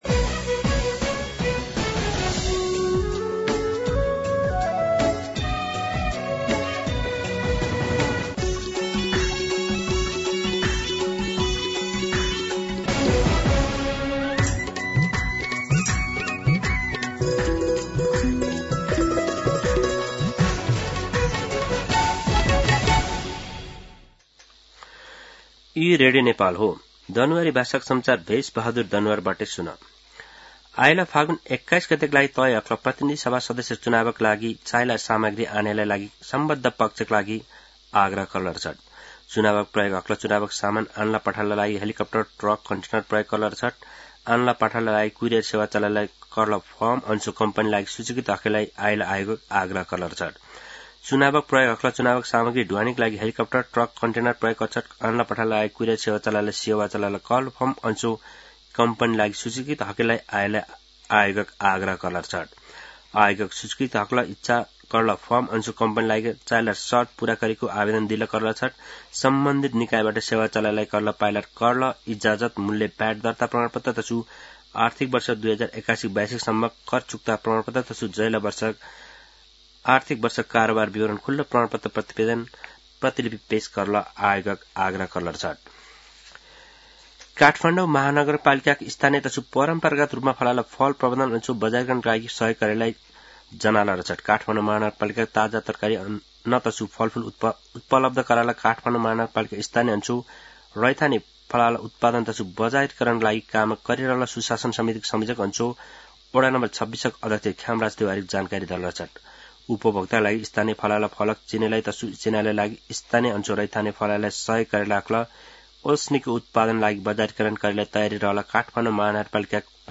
दनुवार भाषामा समाचार : १८ पुष , २०८२
Danuwar-News-9-18.mp3